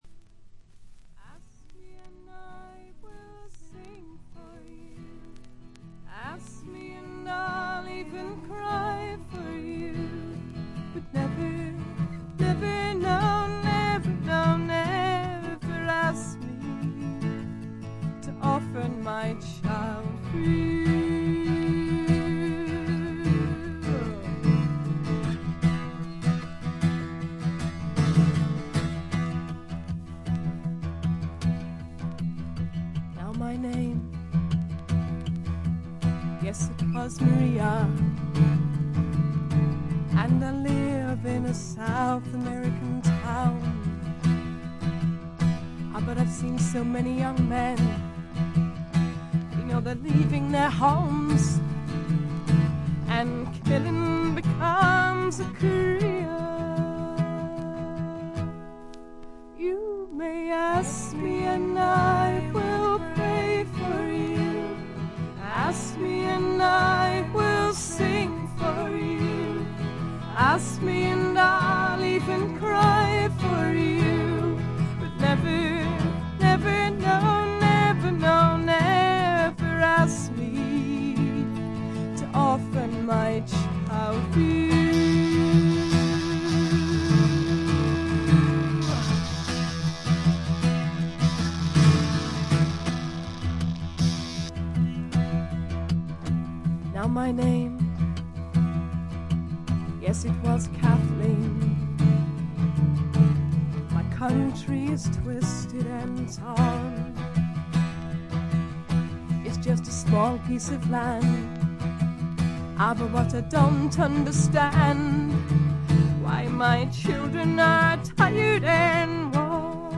A3フェードアウト部から無音部にかけてチリプチ。
試聴曲は現品からの取り込み音源です。
Vocals, Acoustic Guitar, Electric Guitar